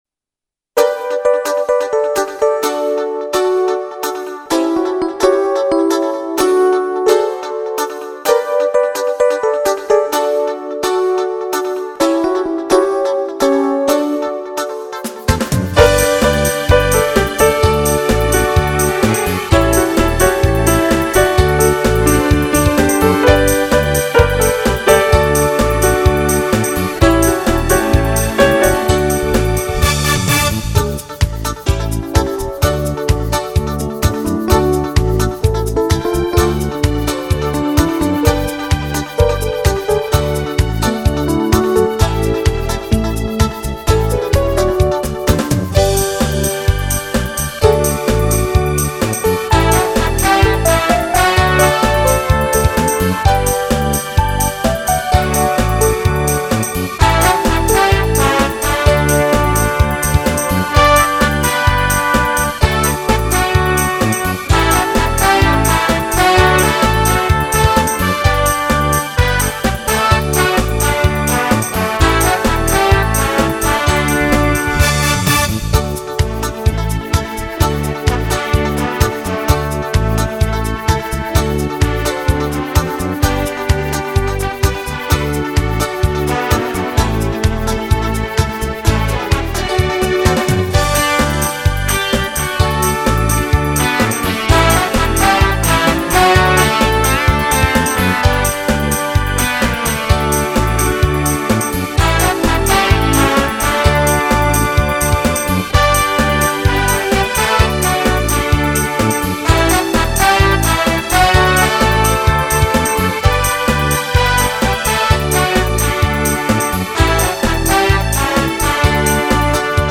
Дитячі